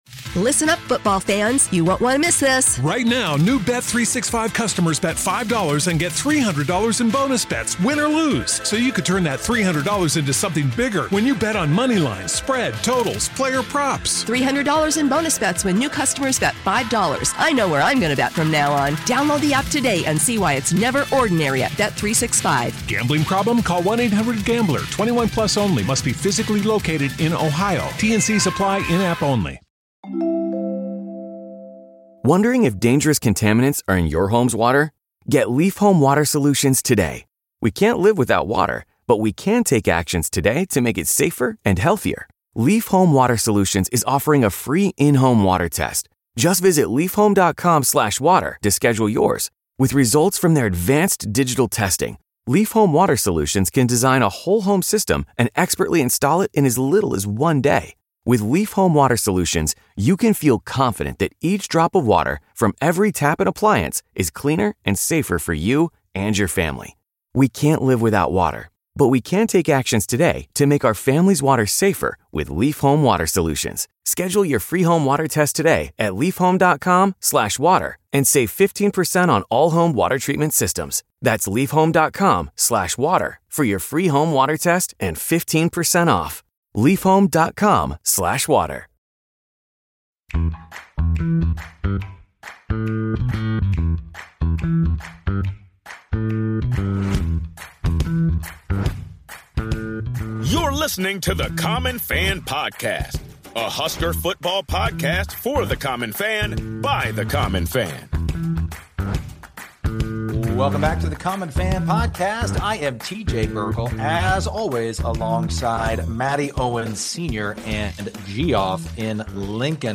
Broadcasting legend Tim Brando joins the Common Fans to discuss the upcoming season, the outlook for Nebraska, his favorite to win the Big Ten, his old friend Lee Corso, plus much more!